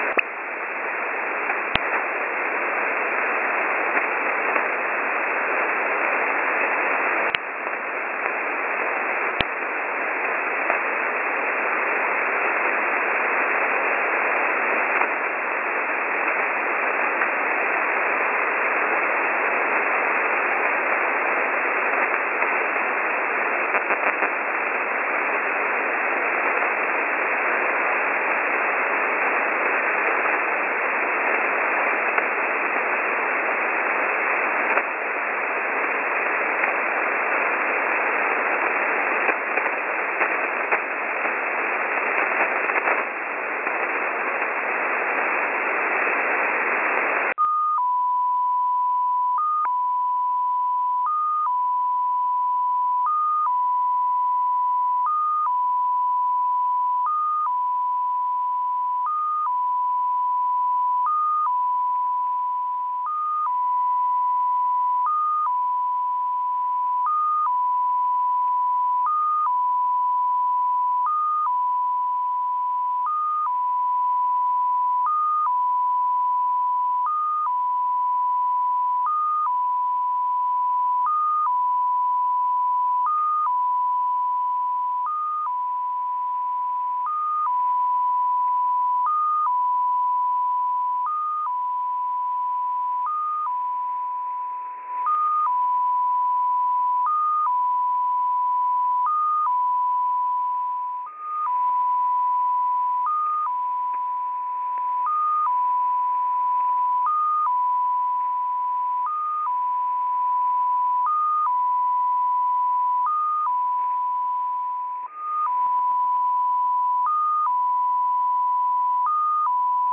Time (UTC): 1520 Mode: USB Frequency: 14538 Recording: websdr_recording_start_2015-10-06T15_19_15Z_14538.0kHz.wav Waterfall Image: XPA2 Decode 14538 KHz 1520z 1620 BST 061015.png Date (mm/dd/yy): 100815